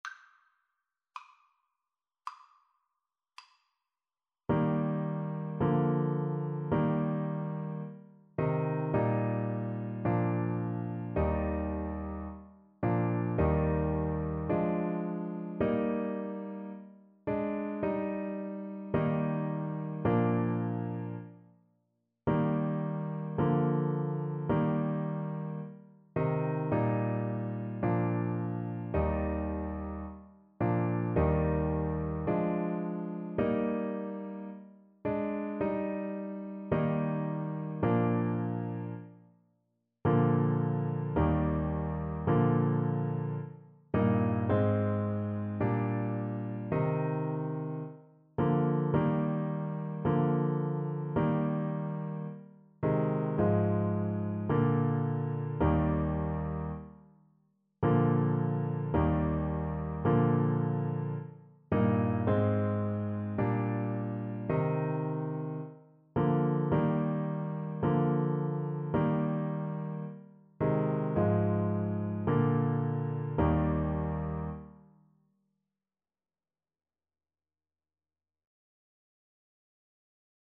Play (or use space bar on your keyboard) Pause Music Playalong - Piano Accompaniment Playalong Band Accompaniment not yet available transpose reset tempo print settings full screen
• Piano
Eb major (Sounding Pitch) F major (Trumpet in Bb) (View more Eb major Music for Trumpet )
= 54 Slow